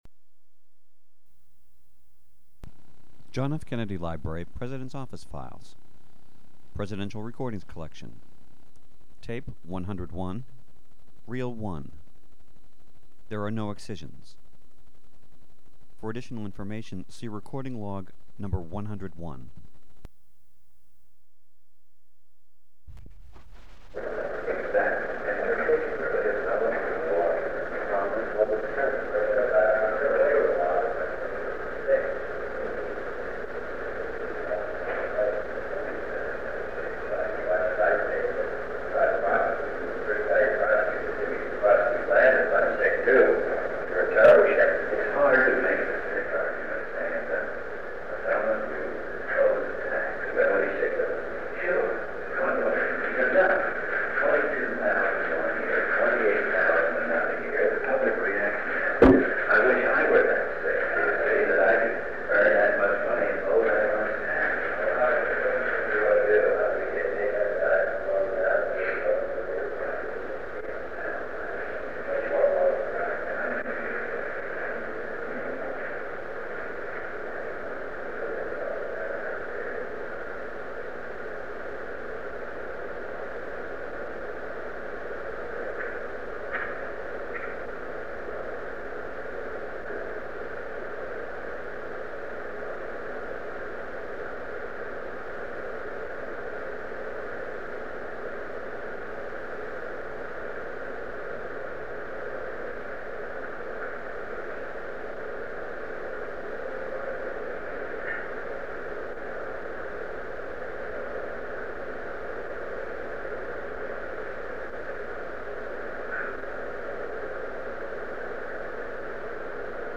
Meetings: Tape 101/A37. [Topic Uncertain; Recording is Largely Unintelligible], July 1963 | Miller Center
Sound recording from July 1963 without distinct conversations.
Distant conversations follow, as well as typical office noises such as typing, telephones ringing, and doors closing.